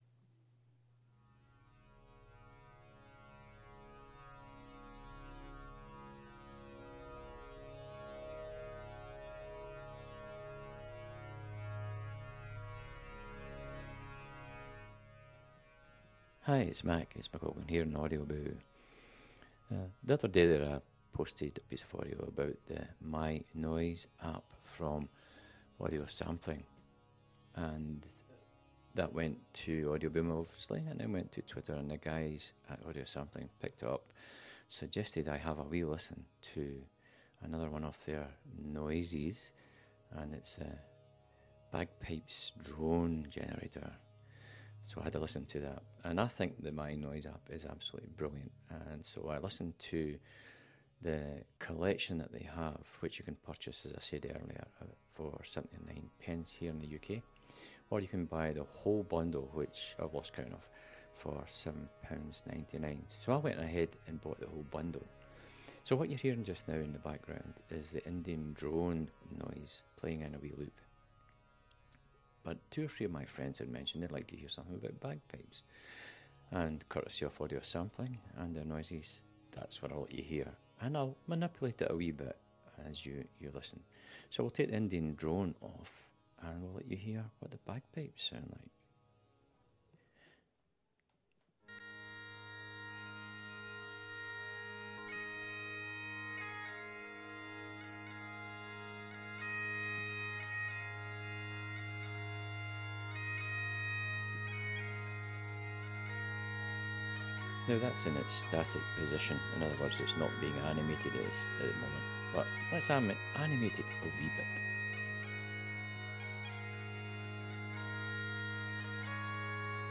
MyNoise app - Bagpipes.